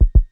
ACE3 / addons / medical / sounds / heart_beats / fast_2.wav